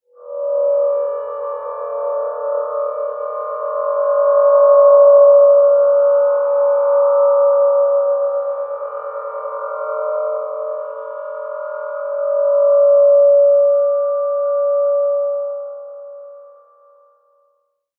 RPH1-D6-mf.wav